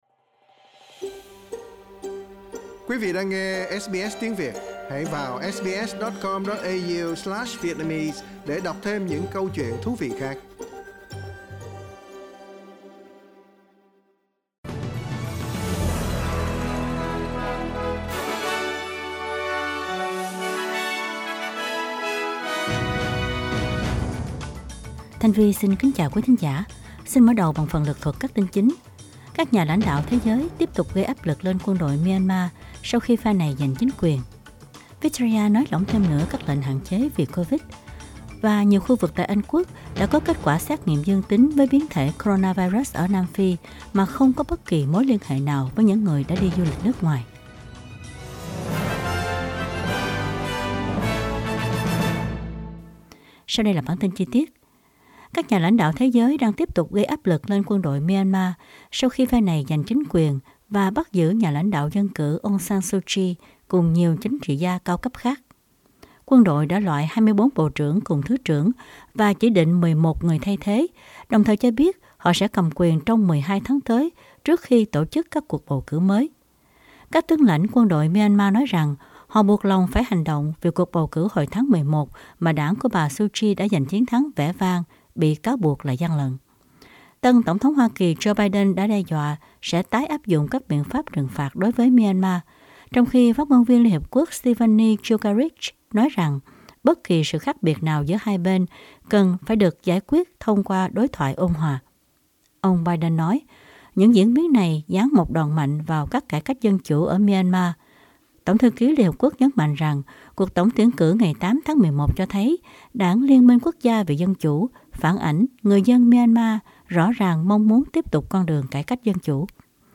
Bản tin chính trong ngày của SBS Radio.
Vietnamese news bulletin Source: Getty